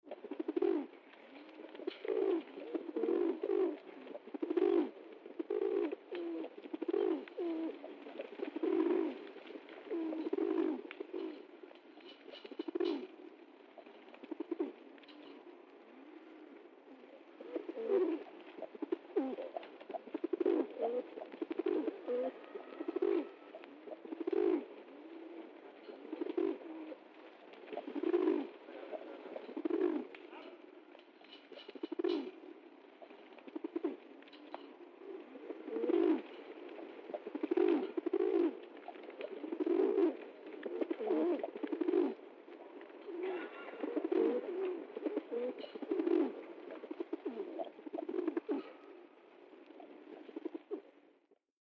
Pigeon cooing